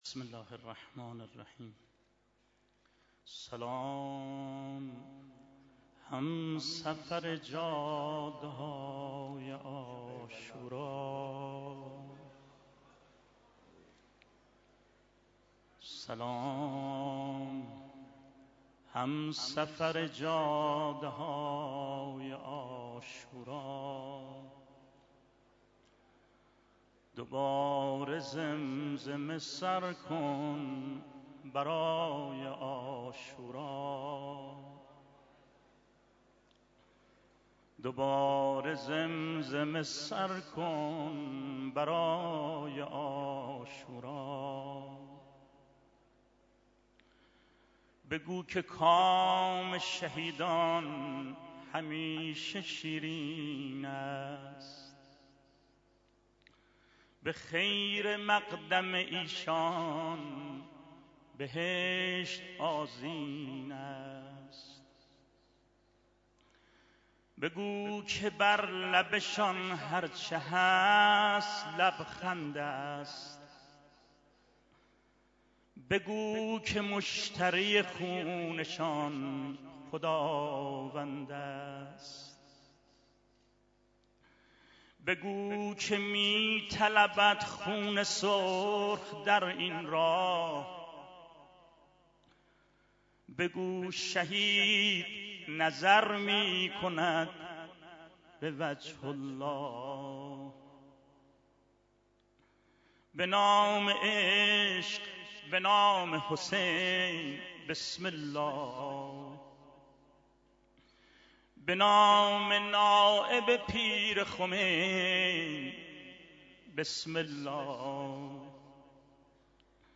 مداحی حاج صادق آهنگران در دیدار دست اندرکاران راهیان نور با رهبر انقلاب